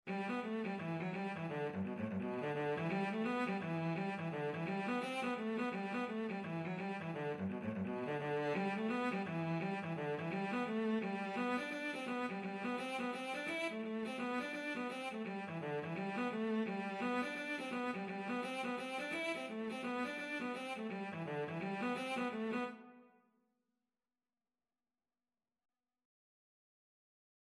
Free Sheet music for Cello
G major (Sounding Pitch) (View more G major Music for Cello )
4/4 (View more 4/4 Music)
Cello  (View more Easy Cello Music)
Traditional (View more Traditional Cello Music)